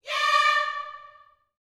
YEAH D 5B.wav